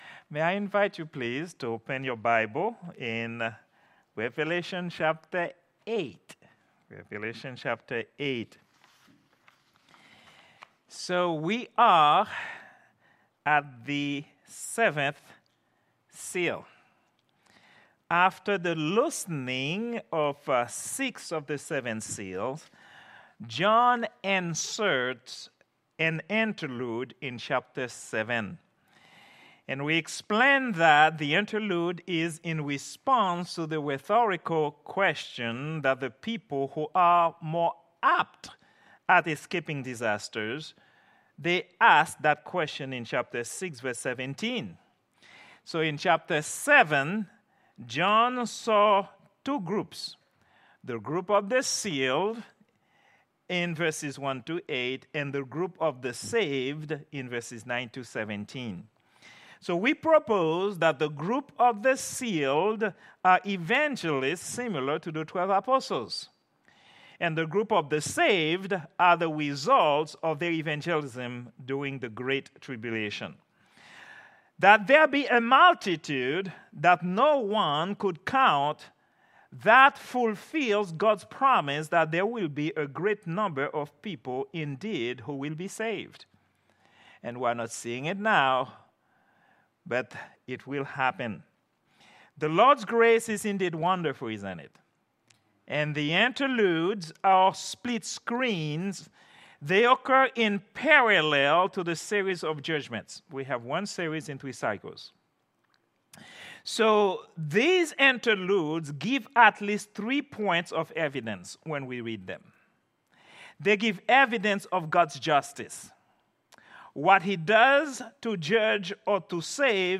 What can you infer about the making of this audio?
Prayer_Meeting_08_28_2024.mp3